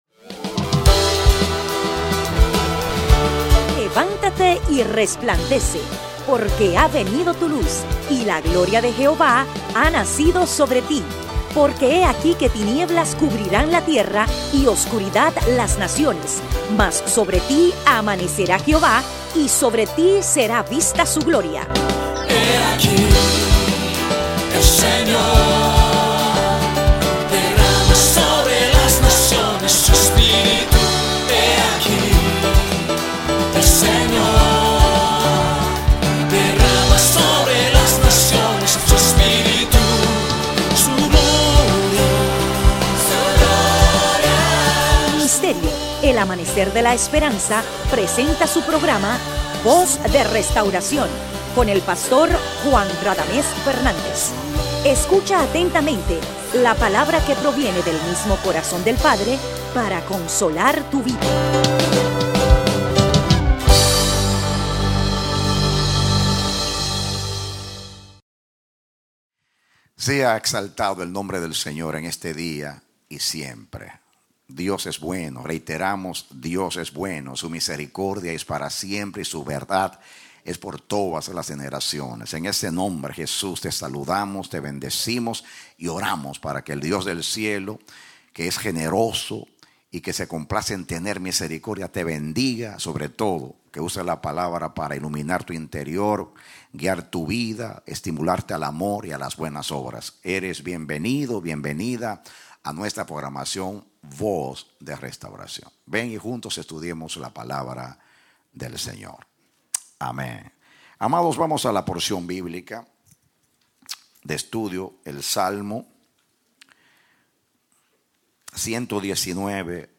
Predicado Domingo 6 de Octubre, 2019 Verso: Salmos 119:133 Romanos 13:1-7 Éxodo 25:8-9 Éxodo 39:32-43 Éxodo 40:33-38 Génesis 1:26-28